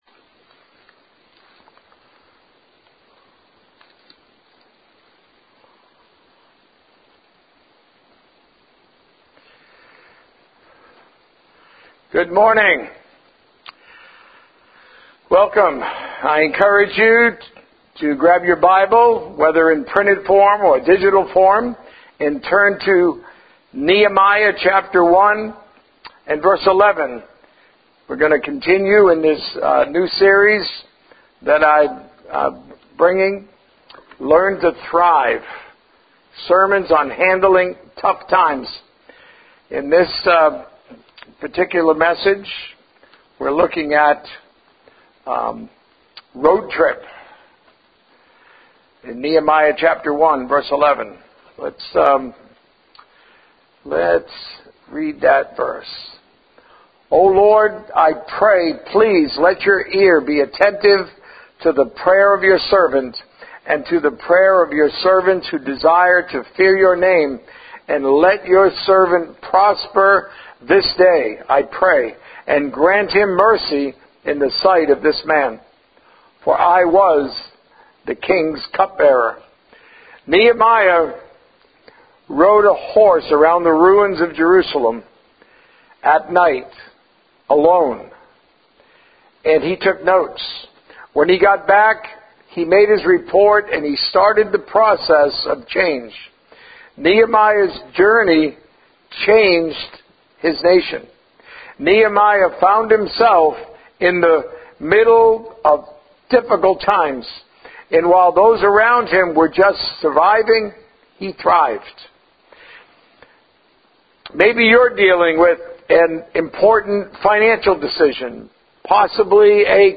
Sermons on handling tough times